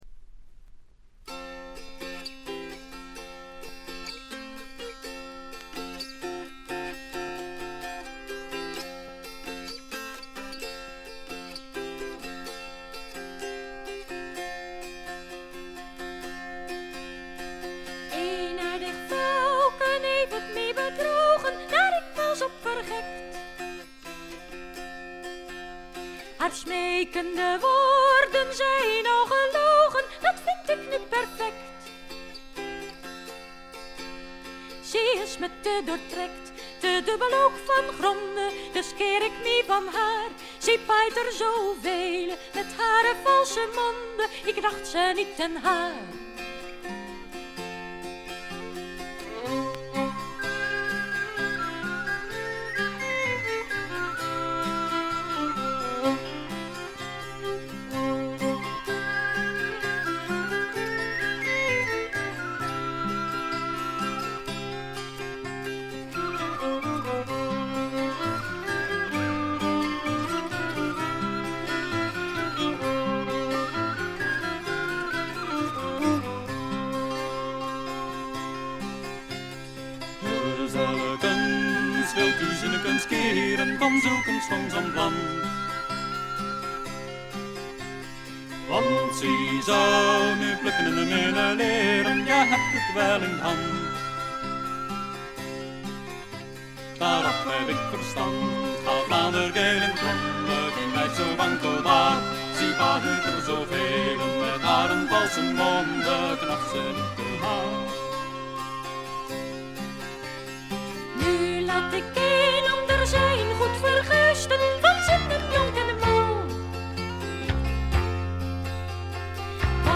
A1中盤でプツ音2回、A2頭プツ音。
女性ヴォーカルを擁した5人組。
試聴曲は現品からの取り込み音源です。
Recorded At - Farmsound Studio